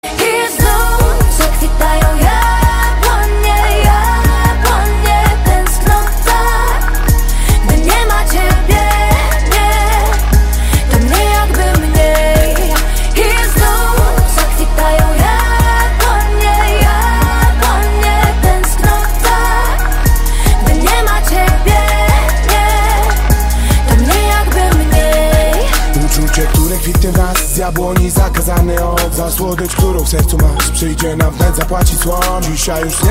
Kategoria POP